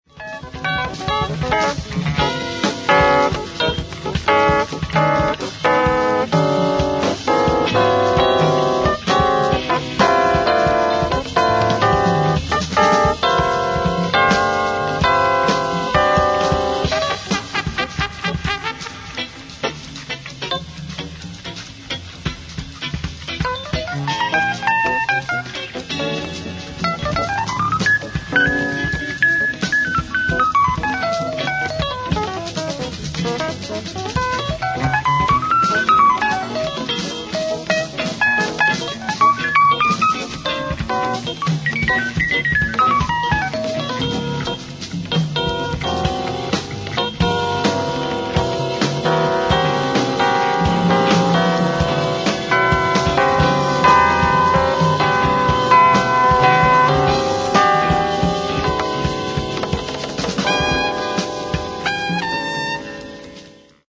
NEVER RELEASED BEFORE SOUNDBOARD RECORDING